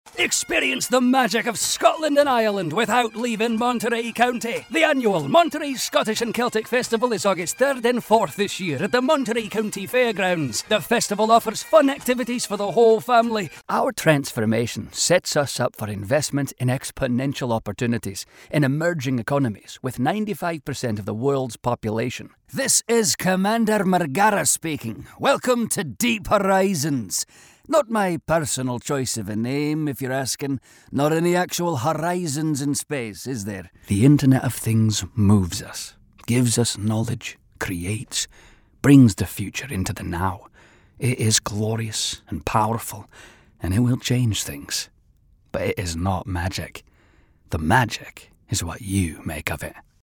Demo
Scottish